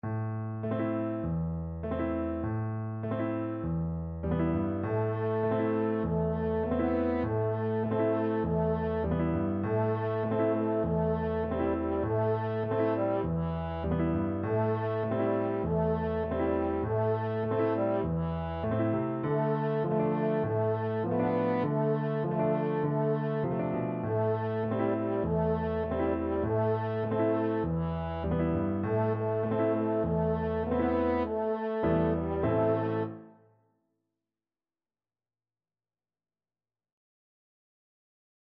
French Horn
Traditional Music of unknown author.
Moderato
A minor (Sounding Pitch) E minor (French Horn in F) (View more A minor Music for French Horn )